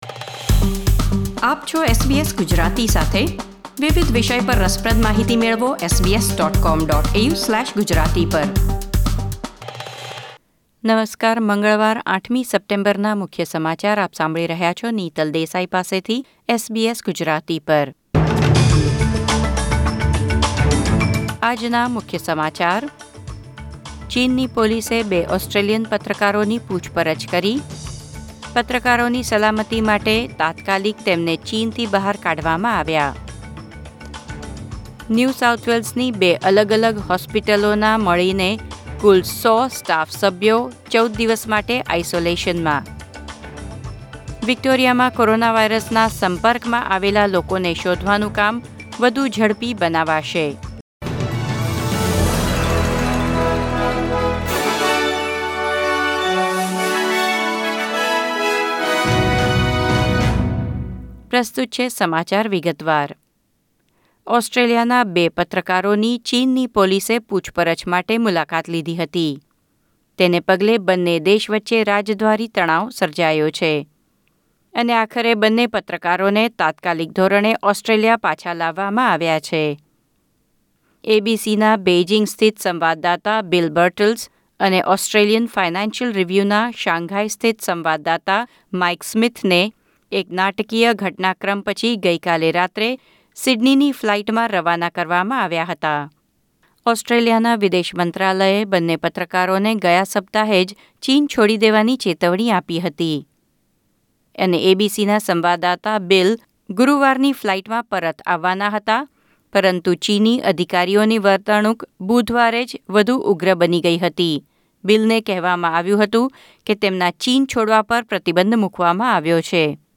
SBS Gujarati News Bulletin 8 September 2020